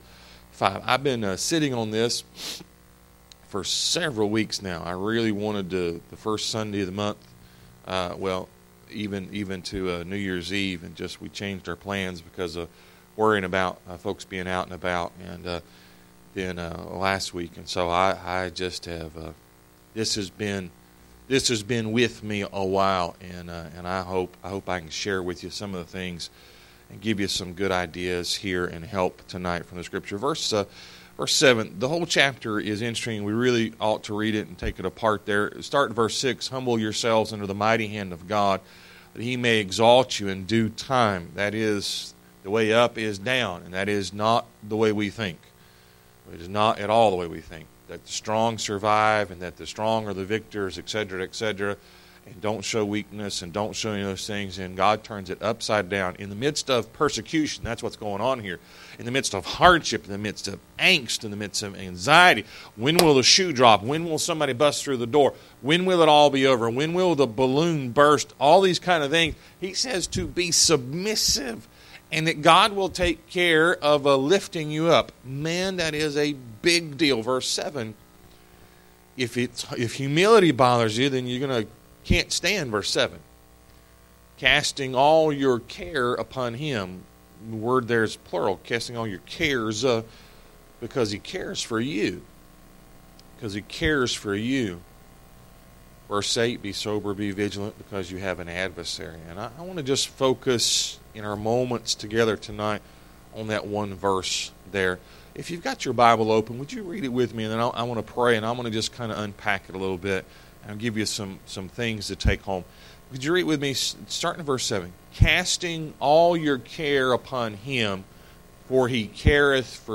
Service Type: Sunday PM